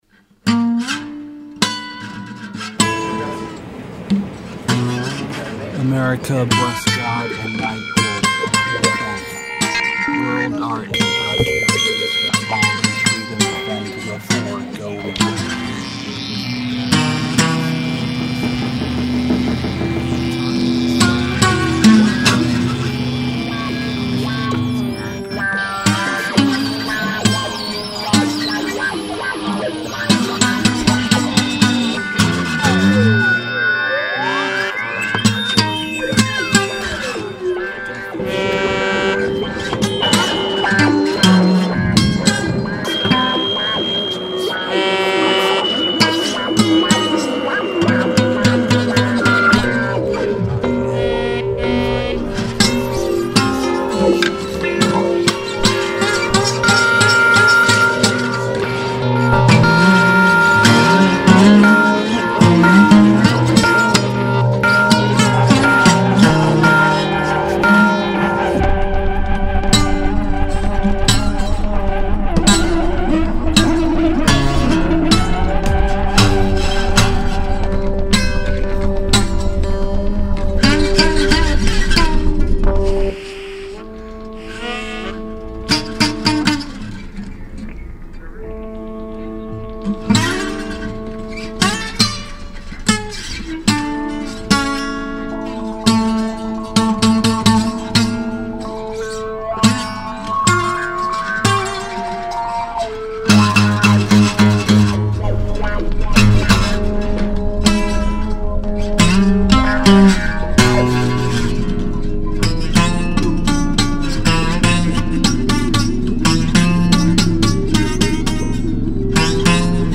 Saxophones and Gufukra rig inc BabbleBox.
Recorded live in one take